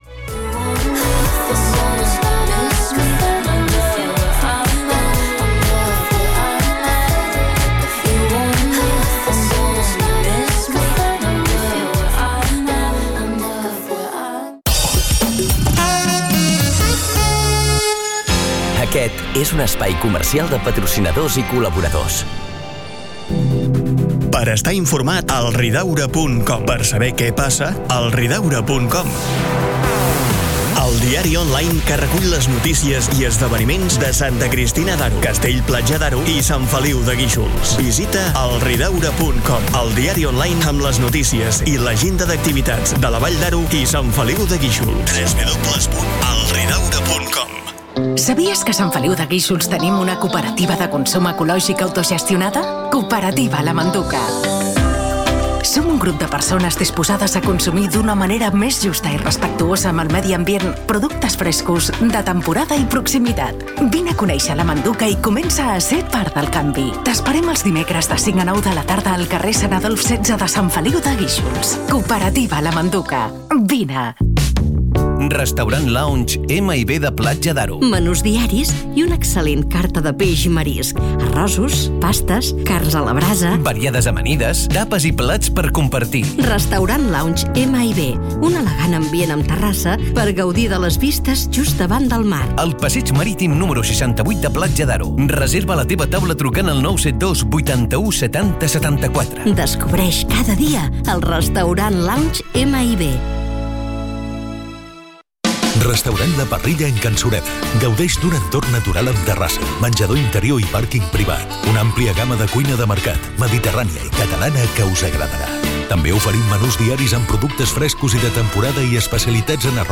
Música, publicitat, identificació de l'emissora i tema musical